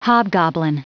Prononciation du mot hobgoblin en anglais (fichier audio)
Prononciation du mot : hobgoblin